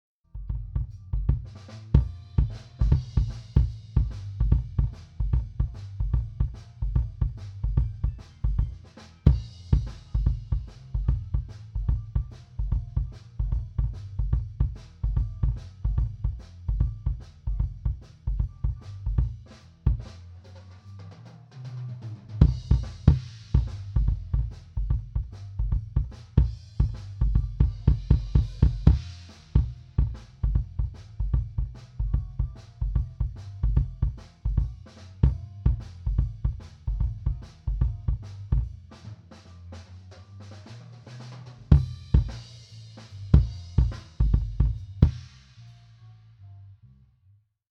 Live-Mitschnitt bei Kneipengig - Bericht mit Audiobeispielen
BD Reso (Shure Beta52a)
Hier die Soundbeispiele - die Einzelsignale sind roh und unbearbeitet - die Mischung natürlich schon...
Bassdrum Reso
Bassdrum-Reso_Beta52a.MP3